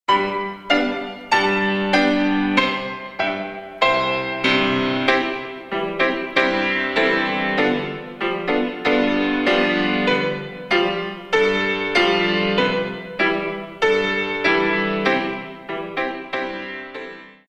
In 2
32 Counts